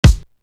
Going Pop Kick.wav